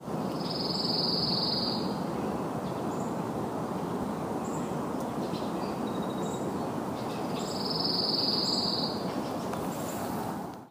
Elsewhere in Troy Meadows, a Pine Warbler was a surprise, singing and moving from one mature White Pine to another in the overgrown area known as the old homestead.
Pine Warbler, Troy Meadows, NJ, June 20, 2014